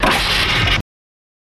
Door Slide Shut With Air Release